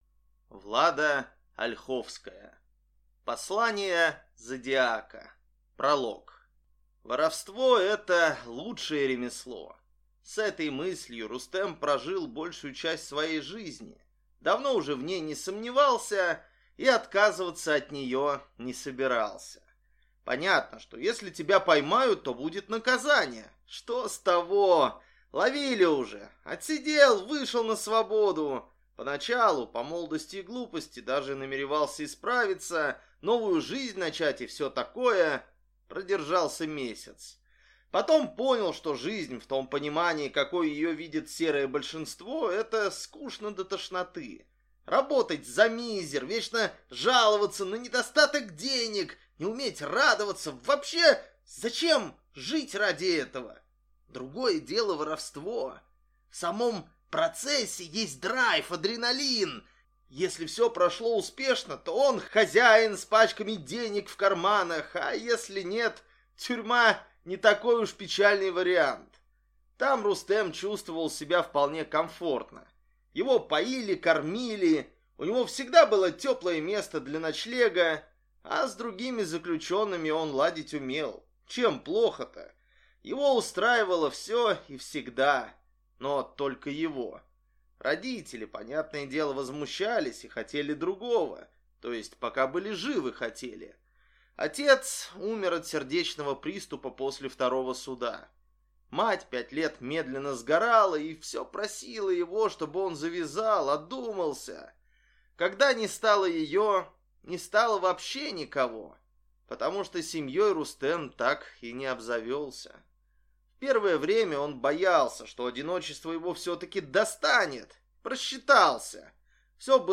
Аудиокнига Послания Зодиака | Библиотека аудиокниг